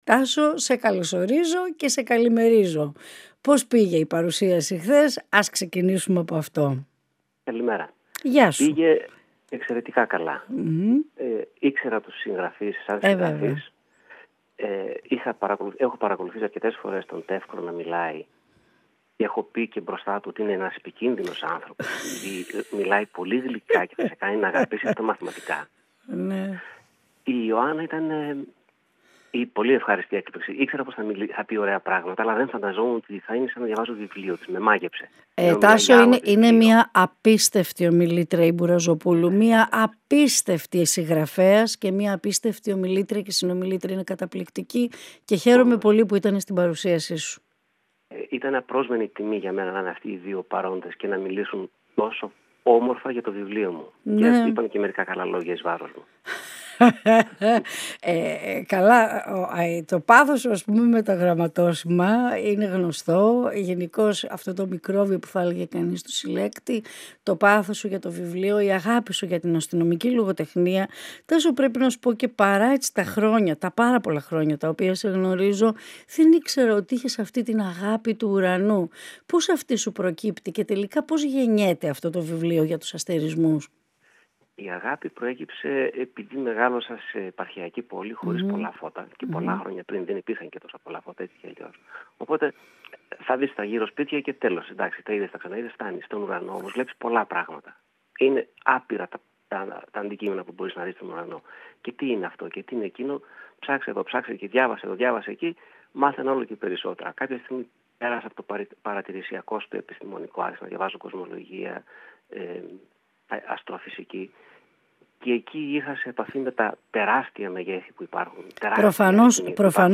Ολιγη Κινηση του Δρομου και των Μαγαζιων Συνεντεύξεις